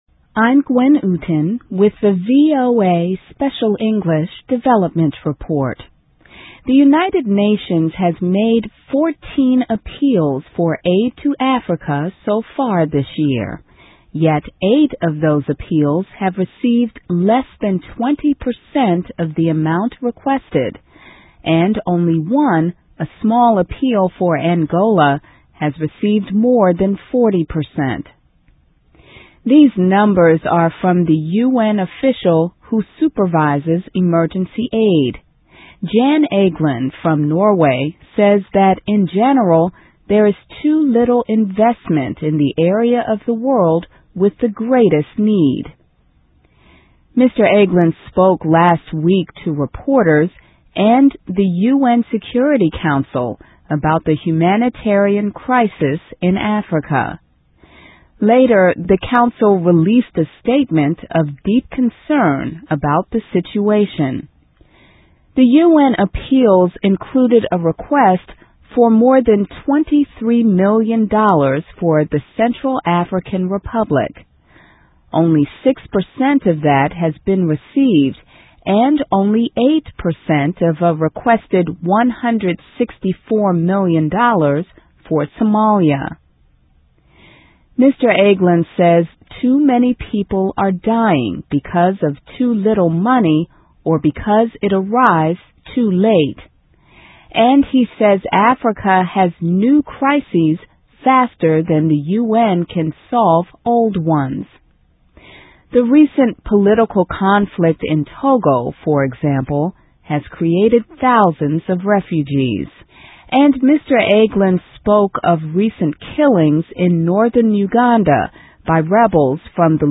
Top Aid Official at U.N. Says World Is Failing Africa (VOA Special English 2005-05-15)